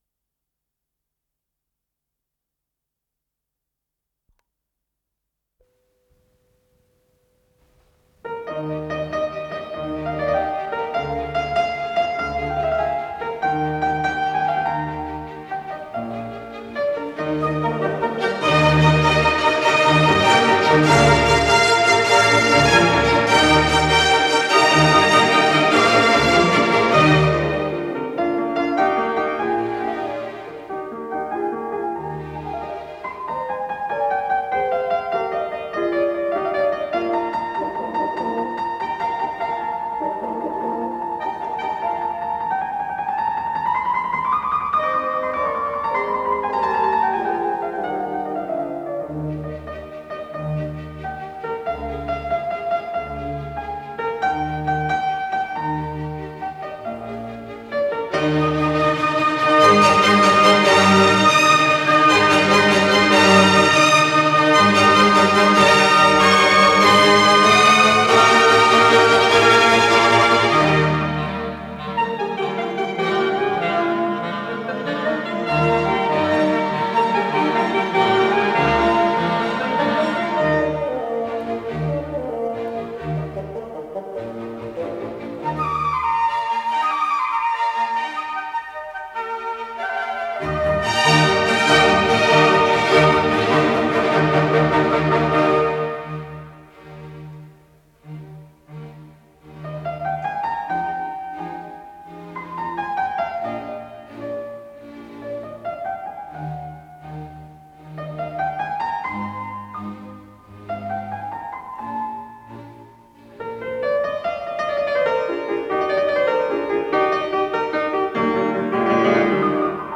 фортепиано
Концерт №22 для фортепиано с оркестром, соч. 484
Ми бемоль мажор